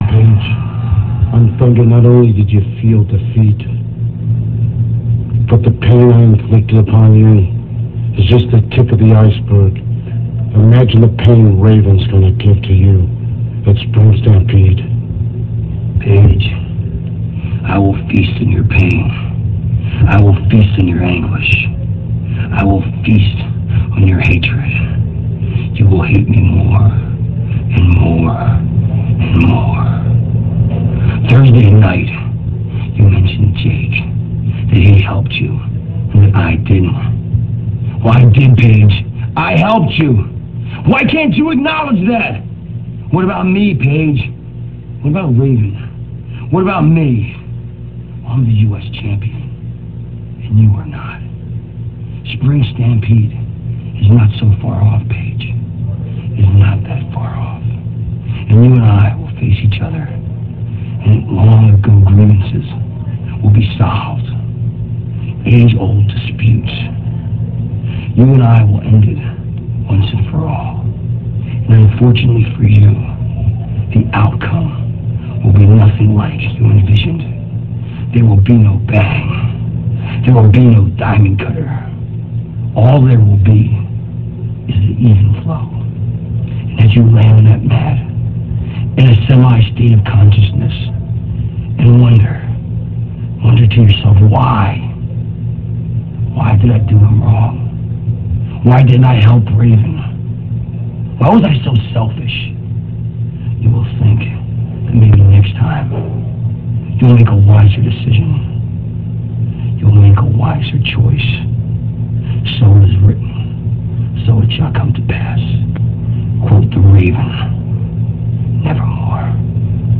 - This speech comes from WCW Saturday Night - [3.28.98]. This speech has both Saturn and Raven taunting Diamond Dallas Page for Raven and DDP's match at WCW Spring Stampede.